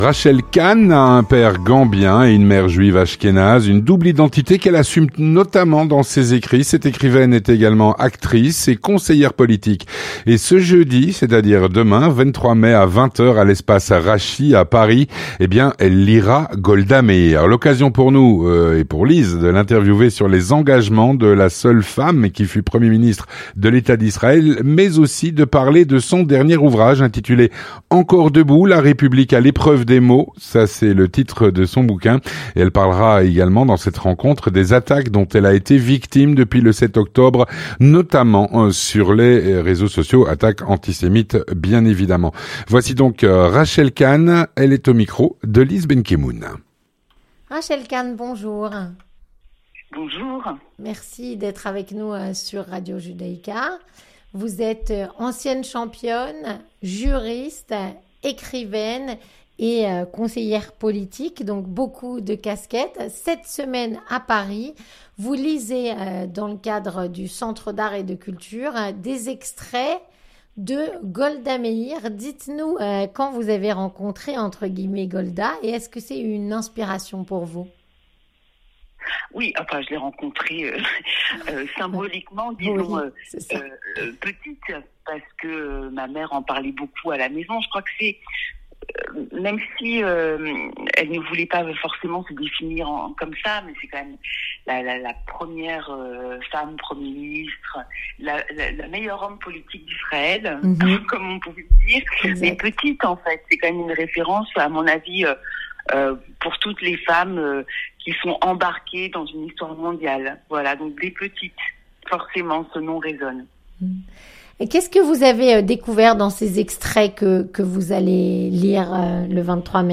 Rencontre - Ce jeudi 23 mai à 20h à l’Espace Rachi de Paris, Golda Meir est à l'honneur.